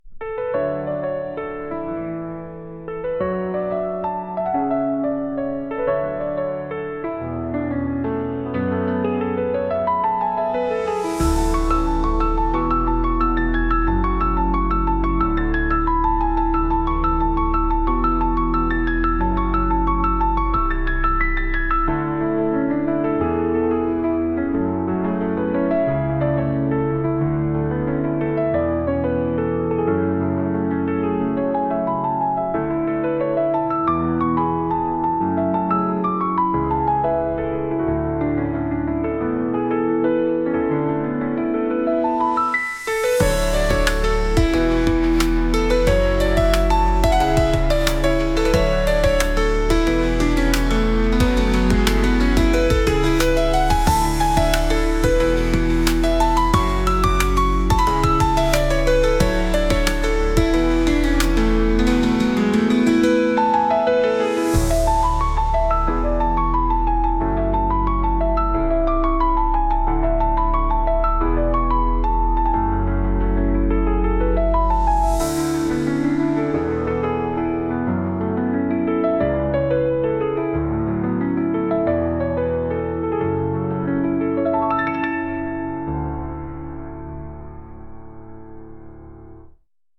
新しいシーンが始まるようなピアノの曲です。